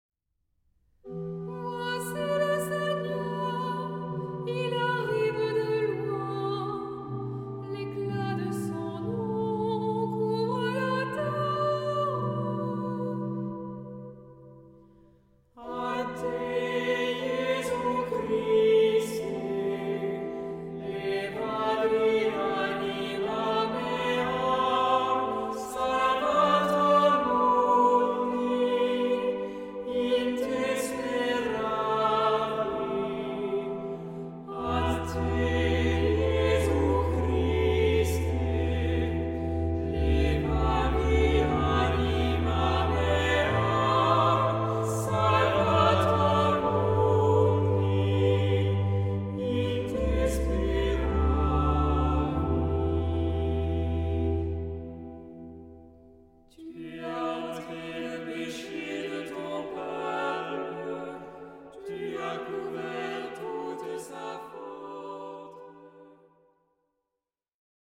Genre-Style-Forme : Tropaire ; Psalmodie
Caractère de la pièce : recueilli
Type de choeur : SAH OU SATB  (4 voix mixtes )
Instruments : Orgue (1) ; Instrument mélodique (ad lib)
Tonalité : mi mineur